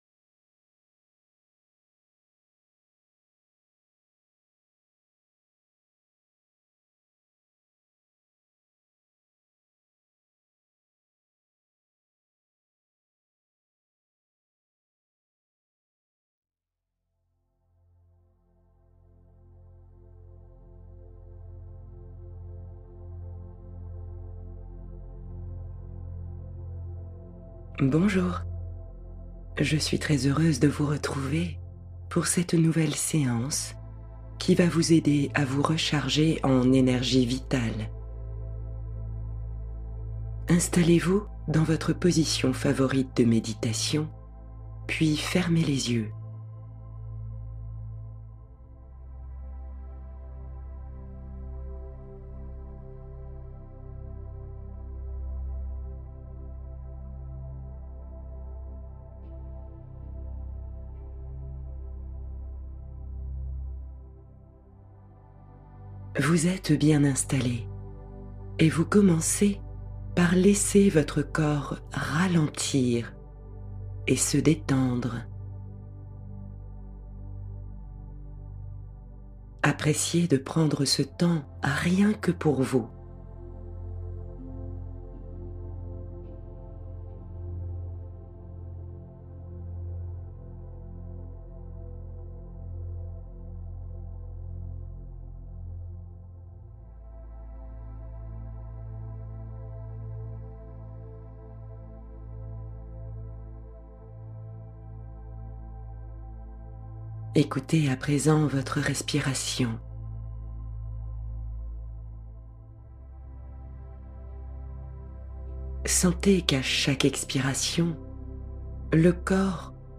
Rencontre avec soi-même : méditation guidée pour se reconnecter à son âme